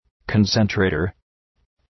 Προφορά
{‘kɒnsən,treıtər}